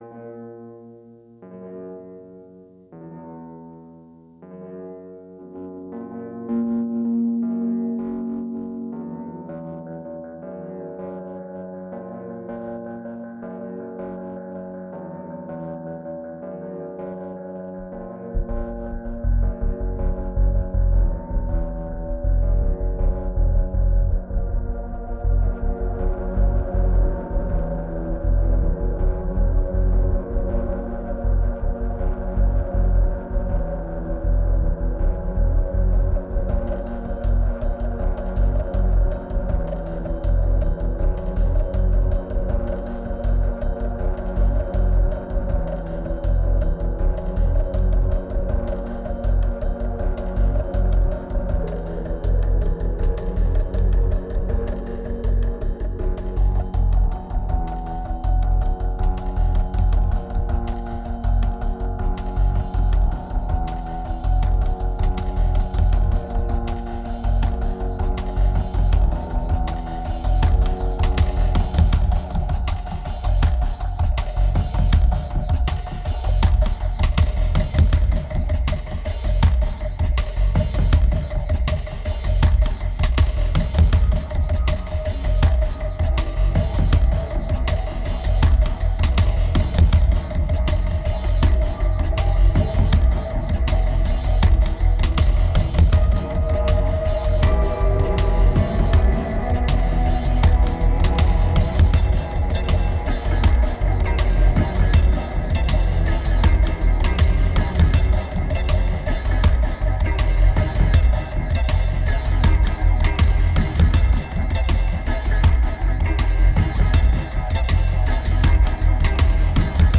2256📈 - 13%🤔 - 80BPM🔊 - 2010-11-26📅 - -76🌟